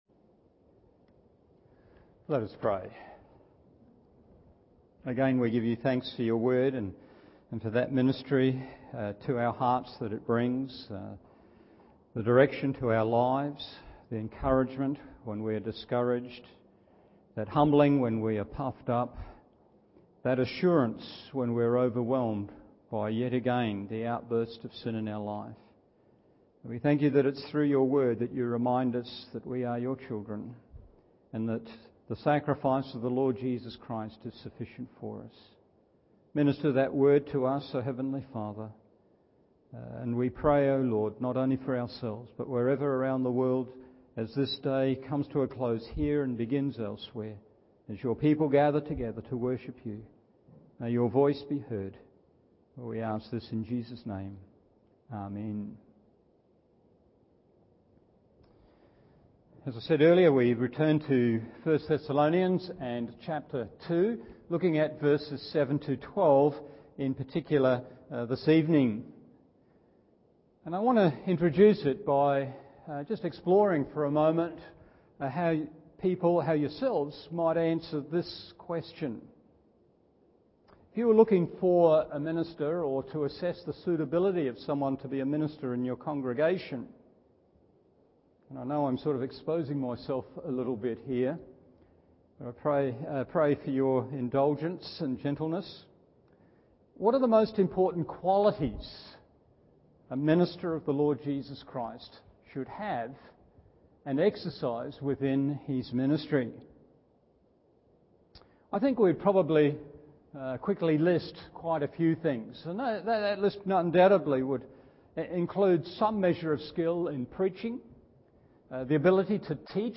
Evening Service 1 Thessalonians 2:7-12 1.